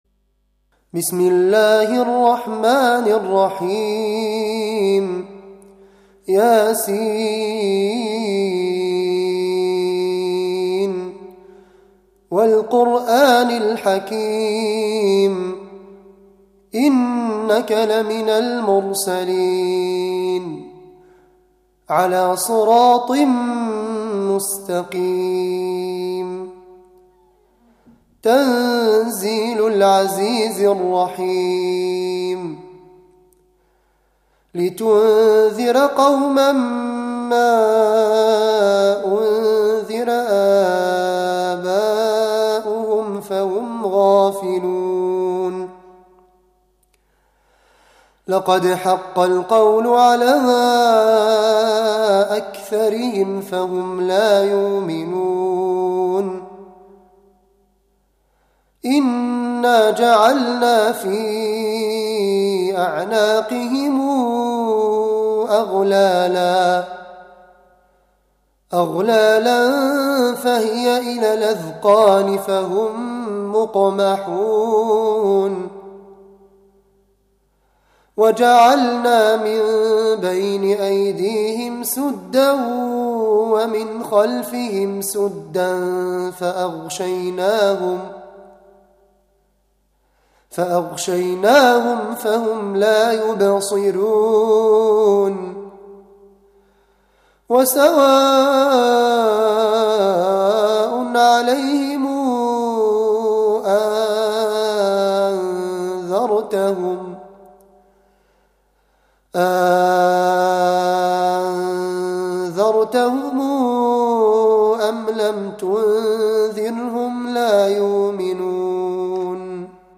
Audio Rezitationen umsere Website Besucher zum hören und downloaden